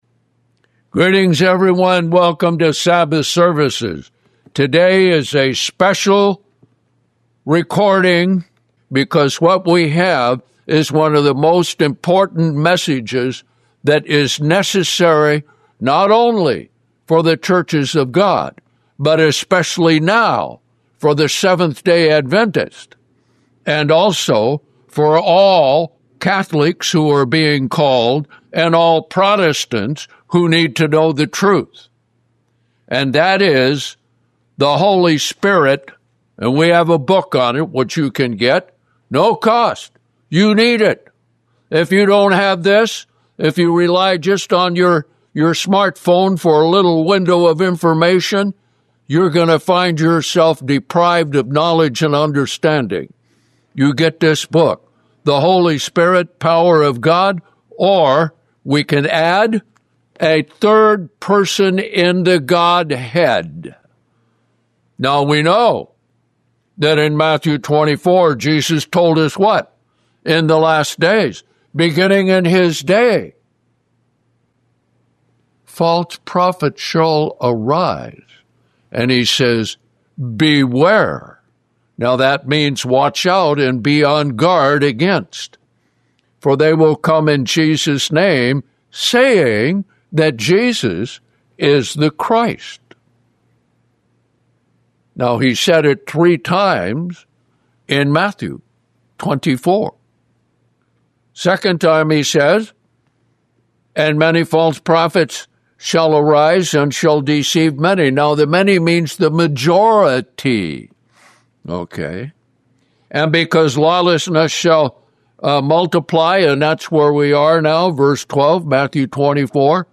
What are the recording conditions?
Greetings everyone, welcome to Sabbath Services.